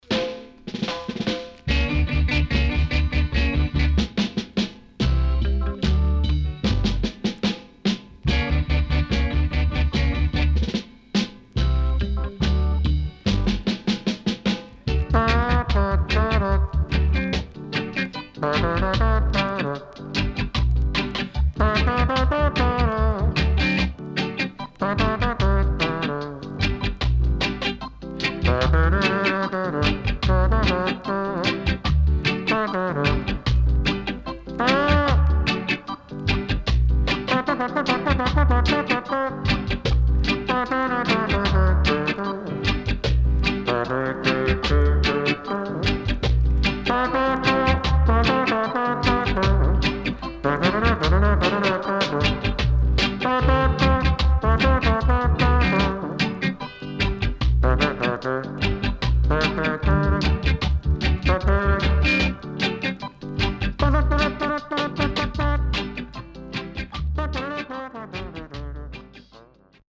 71年 Reggae Take & Trombone Inst
SIDE A:少しノイズ入ります。